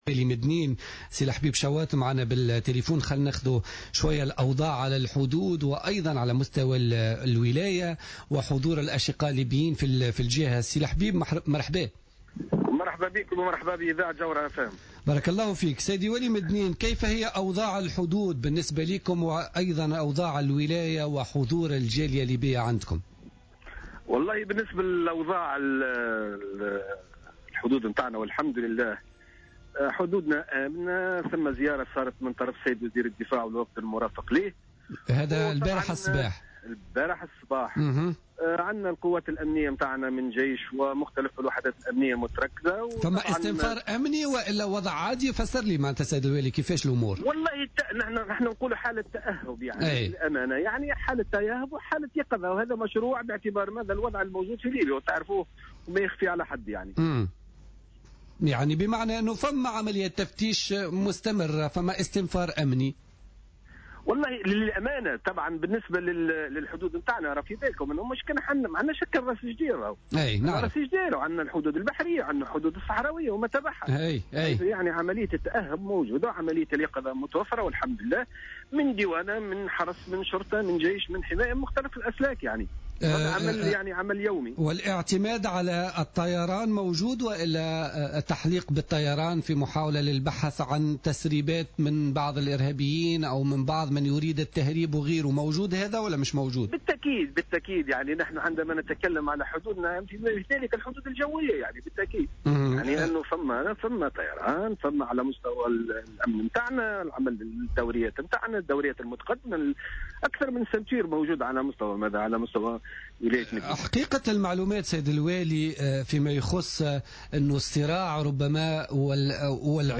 أكد والي مدنين الحبيب شواط في مداخلة له في برنامج بوليتيكا اليوم الإثنين 8 ديسمبر 2014 أن الحدود التونسية الليبية على مستوى ولاية مدنين امنة وتشهد تمركزا لقوات الأمن والجيش والديوانة مؤكدا أن حالة التأهب واليقظة الدائم تخيم على المكان على حد قوله.